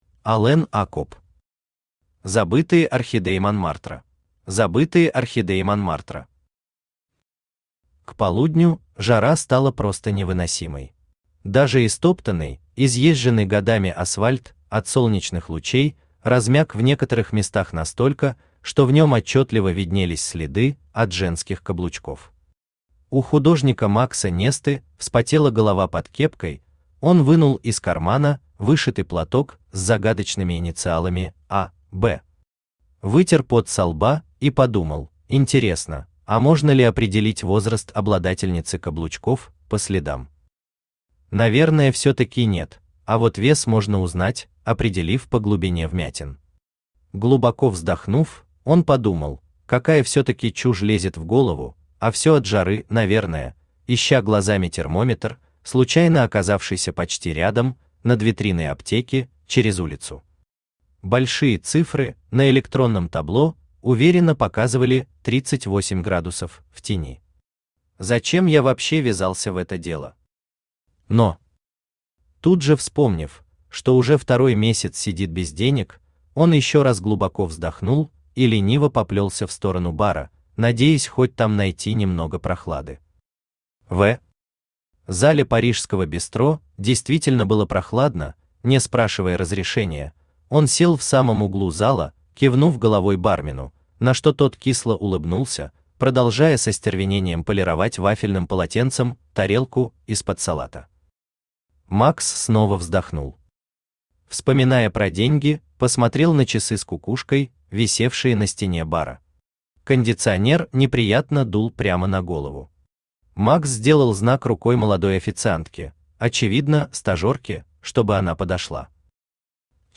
Aудиокнига Забытые орхидеи Монмартра Автор Алэн Акоб Читает аудиокнигу Авточтец ЛитРес.